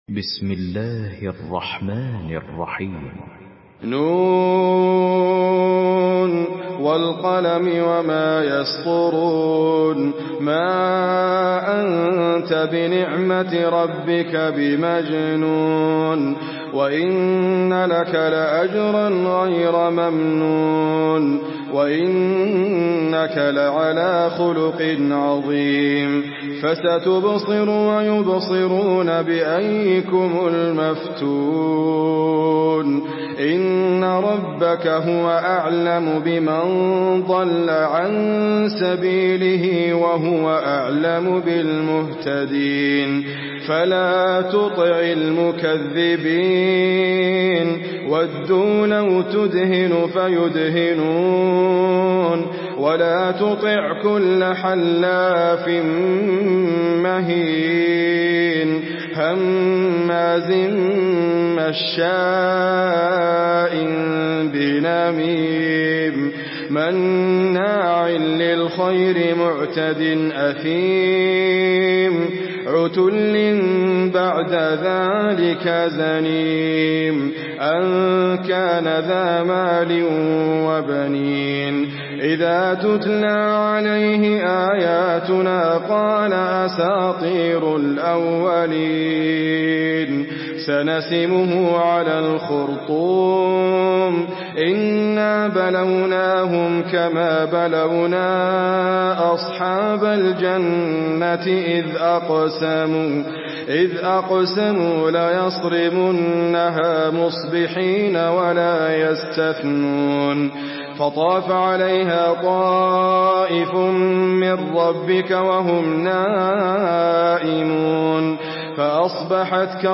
Surah Al-Qalam MP3 by Idriss Abkar in Hafs An Asim narration.
Murattal